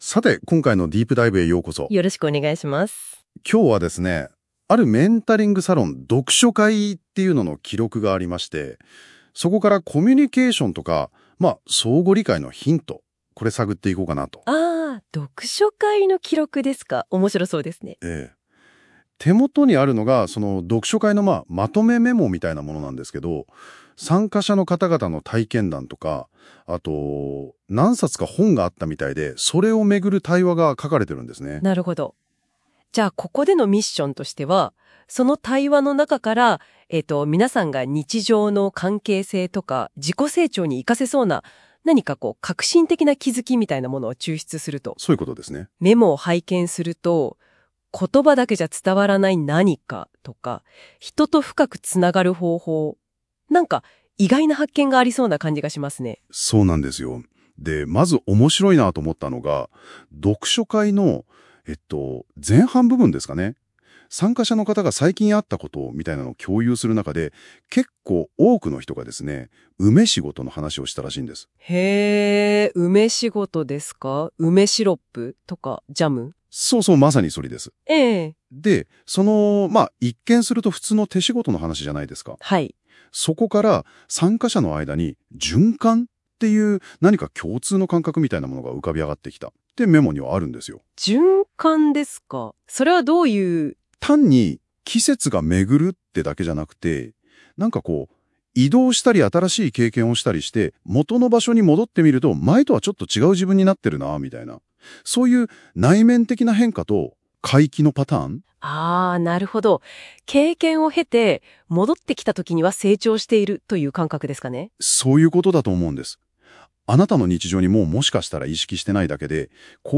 読書会は二部構成。前半は、まず「この1ヶ月の振り返り」から始まります。